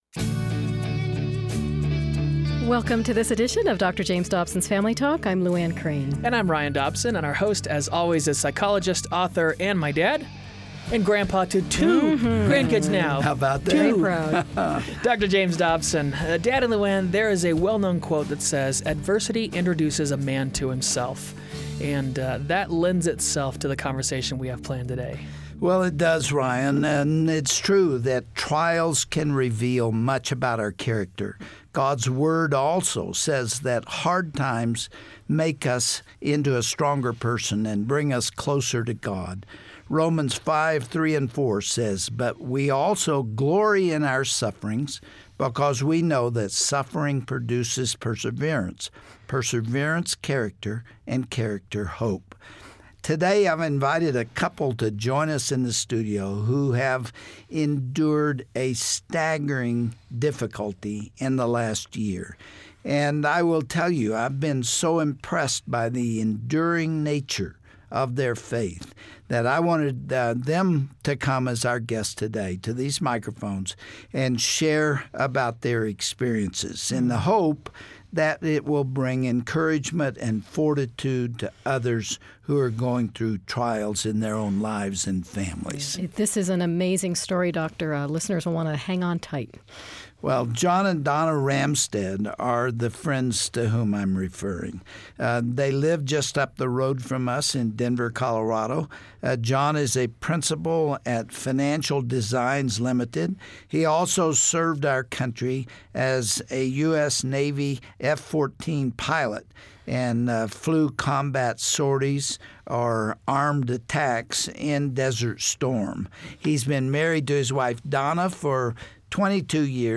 Everyone goes through hard times, but how do you get through life's darkest storms? You will hear from one man who faced a terrifying accident, and is still recovering.